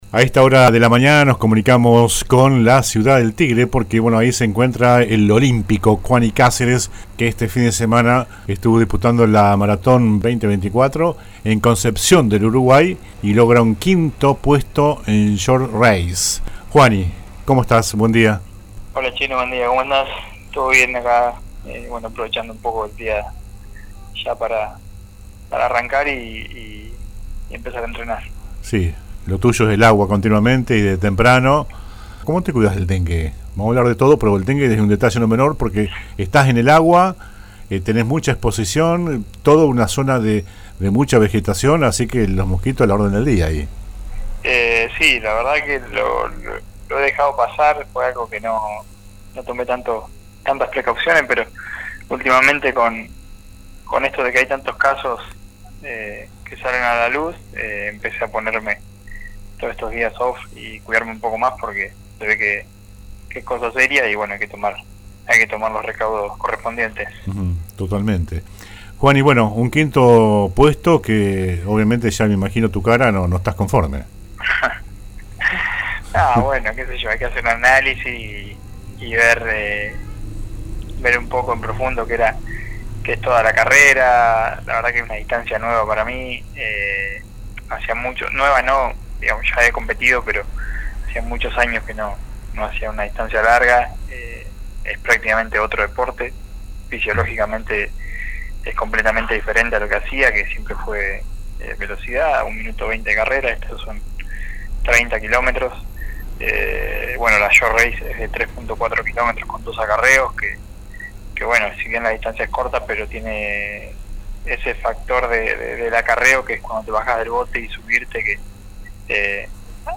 el olímpico Juani Cáceres conversó esta mañana